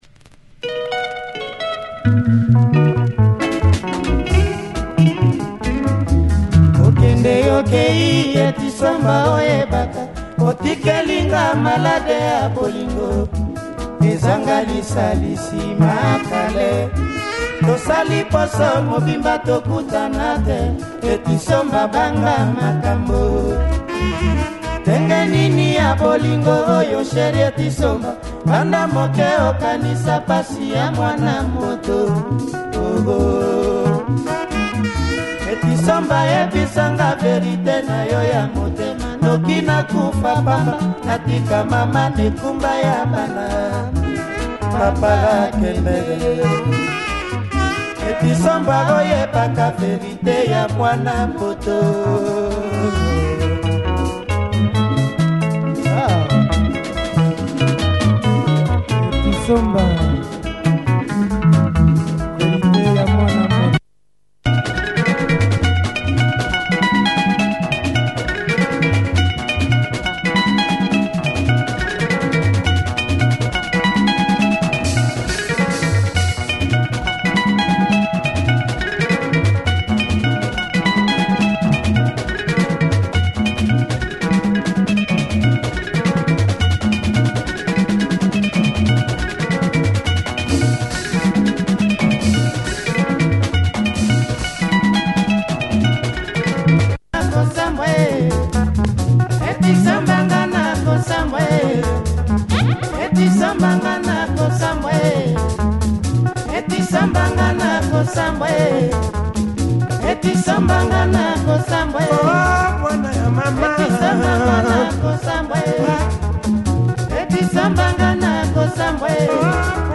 Great breakdown aswell.